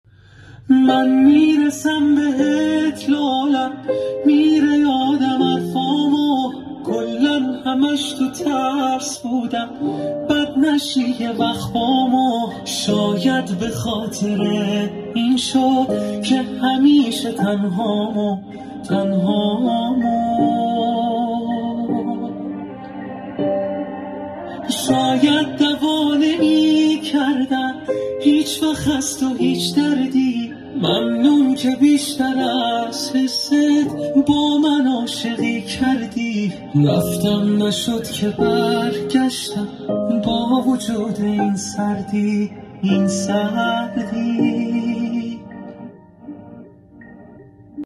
عاشقانه و غمگین
پخش آنلاین دمو آهنگ